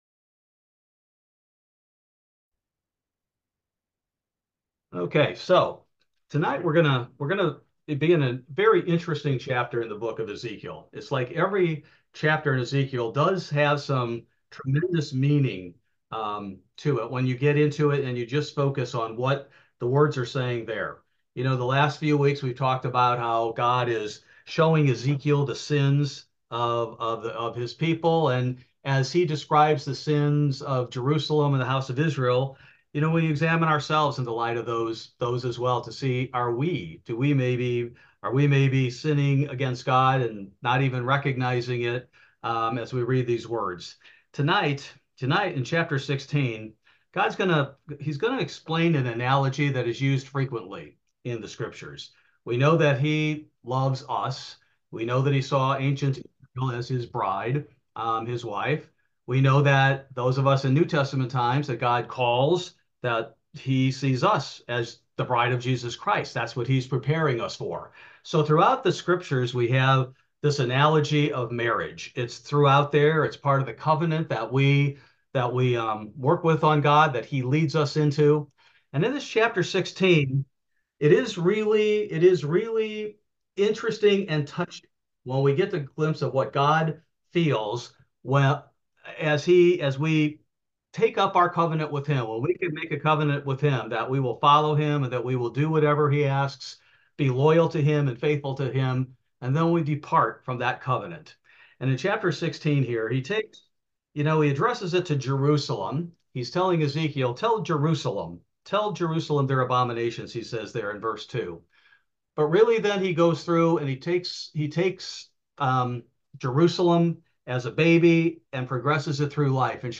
This verse by verse Bible Study focuses primarily on Ezekiel 16, Part 1: Like an Adulterous Wife